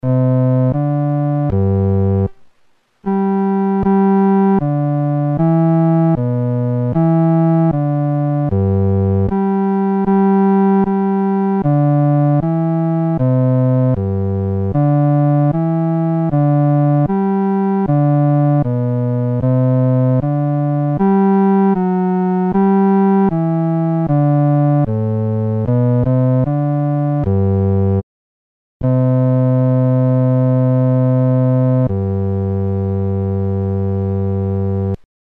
伴奏
男低